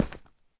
stone1.ogg